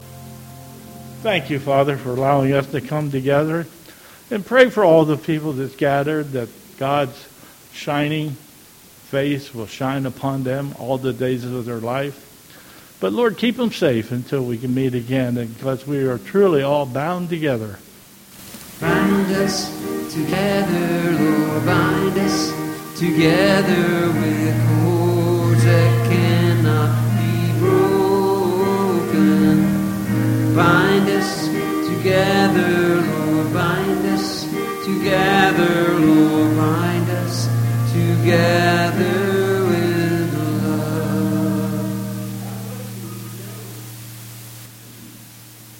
Hymn: "He is Exalted"